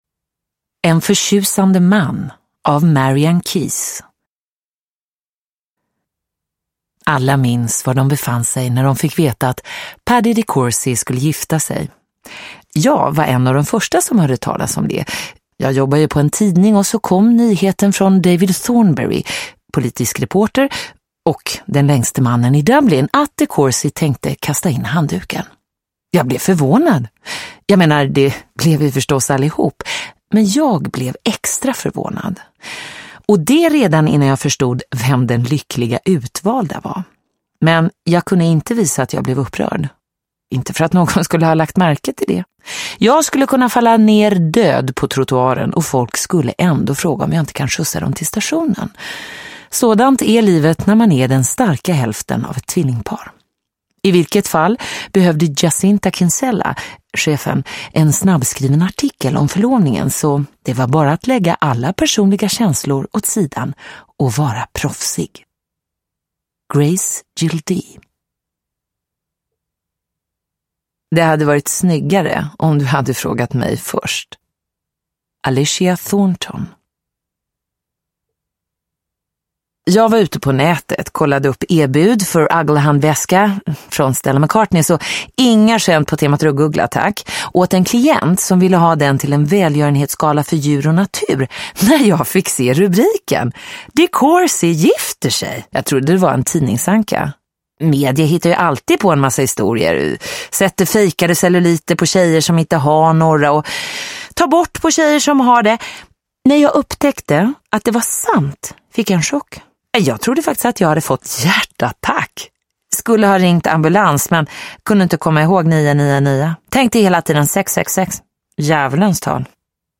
En förtjusande man (ljudbok) av Marian Keyes